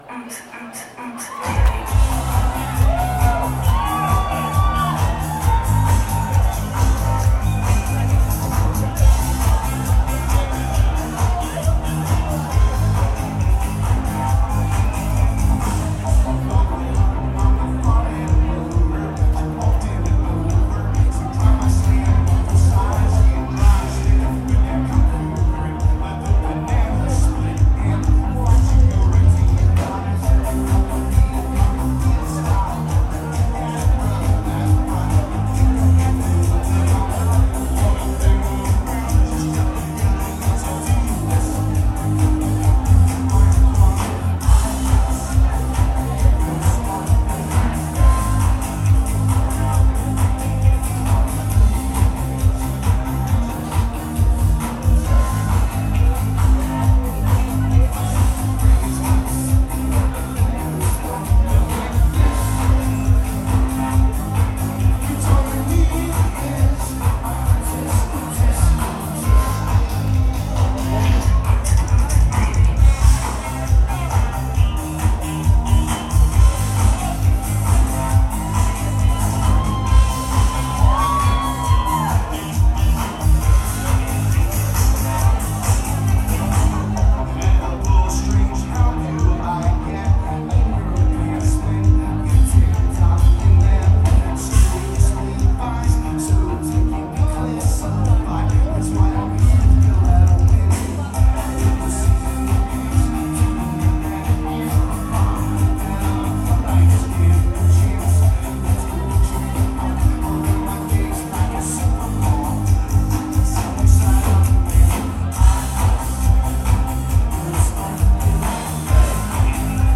mitschnitt-sample: